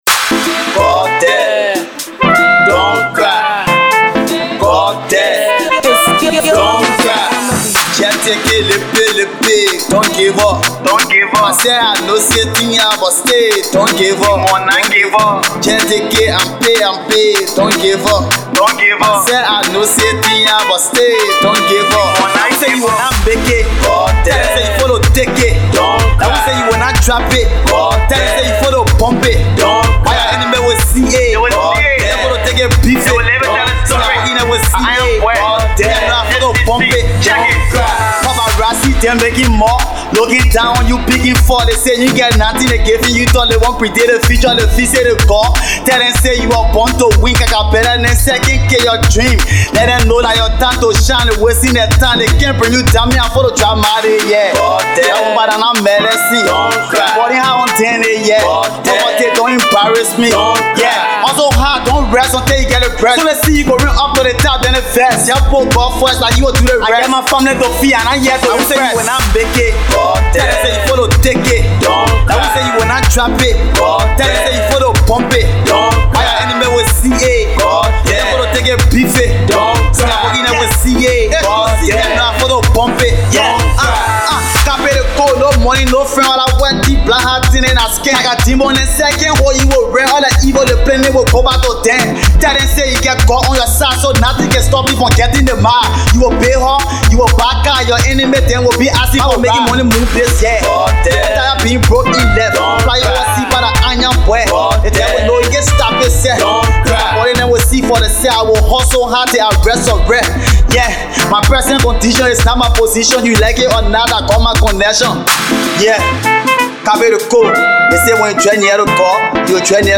Hip-Co